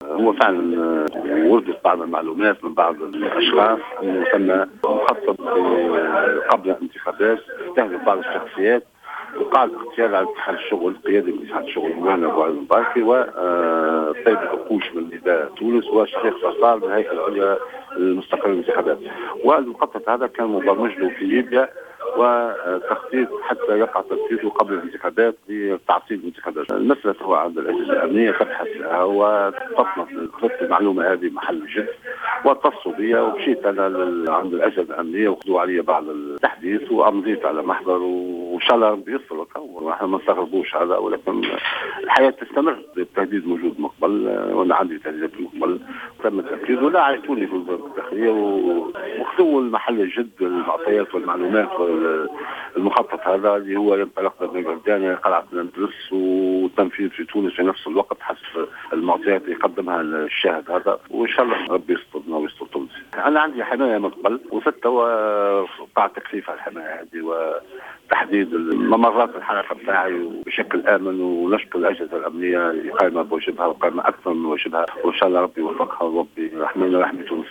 كشف بوعلي المباركي الأمين العام المساعد للاتحاد العام التونسي للشغل في تصريح اليوم السبت ل"جوهرة أف أم" عن وجود مخطط لاغتياله إلى جانب الأمين العام لحركة نداء تونس الطيب البكوش وكذلك رئيس الهيئة العليا المستقلة للانتخابات،شفيق صرصار.